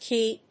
kʰi.mp3